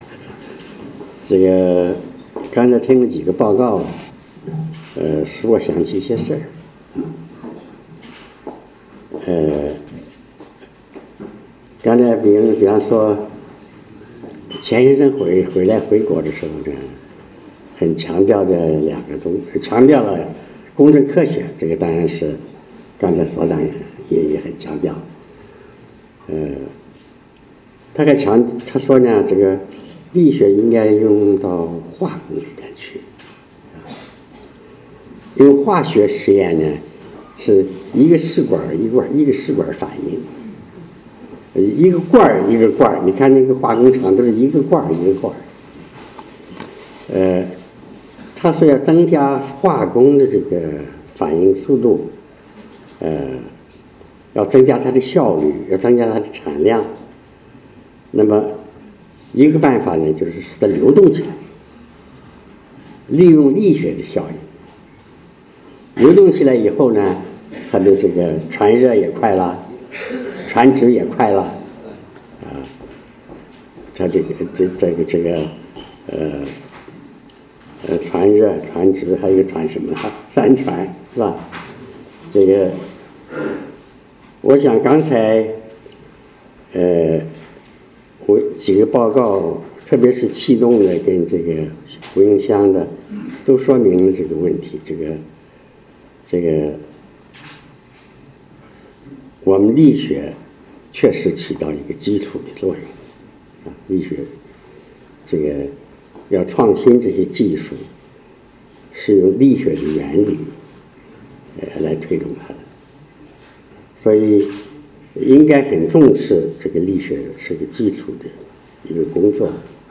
在“庆祝中国科学院力学研究所成立60周年学术报告会”上郑哲敏院士发表讲话